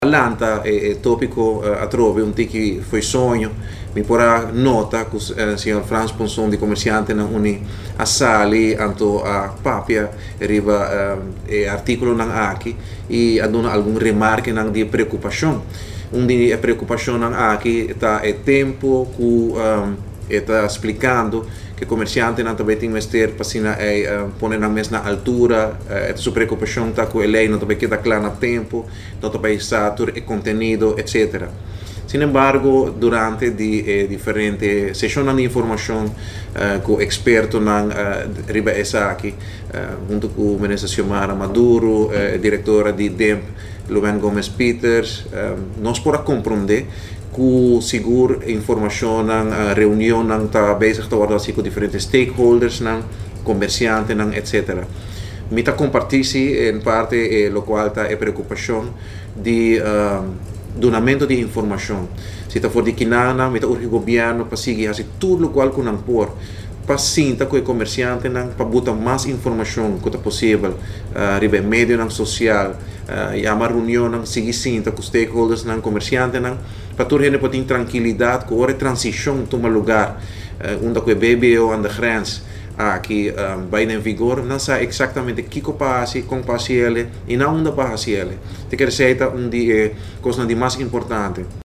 Parlamentario Marco Berlis den conferencia di prensa ta elabora riba e BBO na frontera, segun Berlis e ta contento cu comerciantenan uni a tuma nota riba e situacion di BBO na frontera y a sali afo, alabes ta urgi gobierno pa duna mas informacion riba e topico aki.